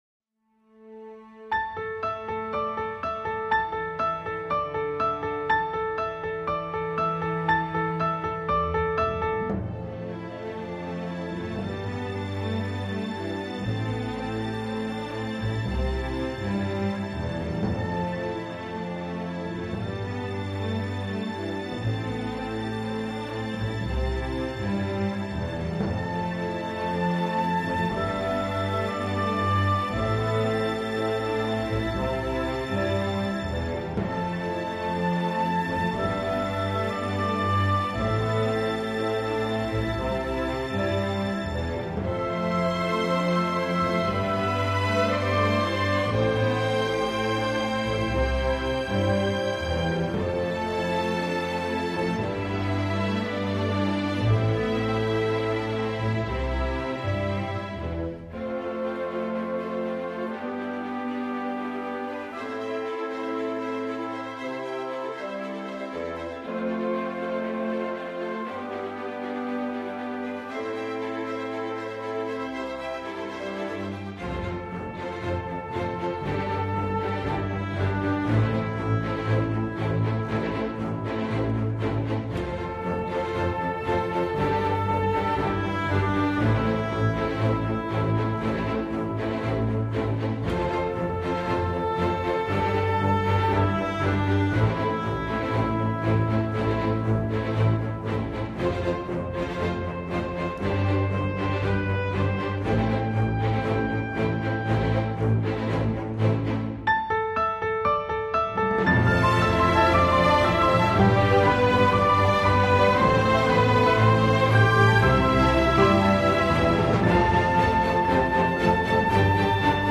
This one is good as generic "good" theme or main menu theme.